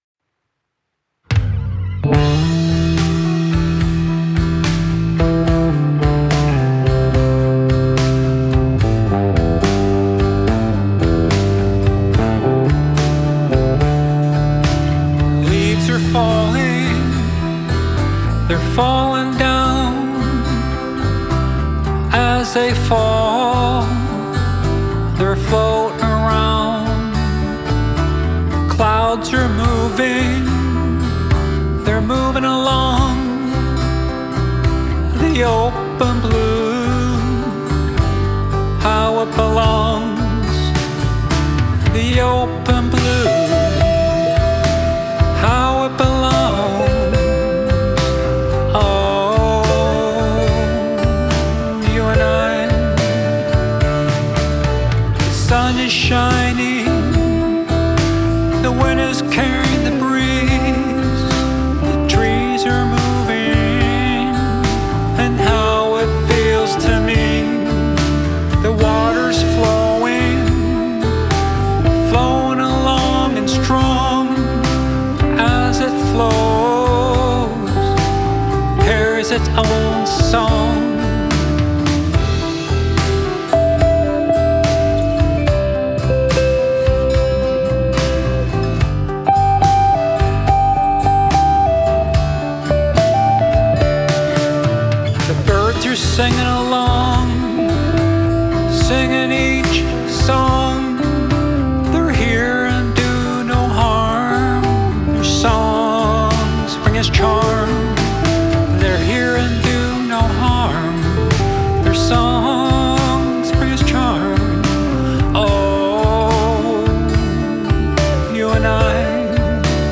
Canadian singer/songwriter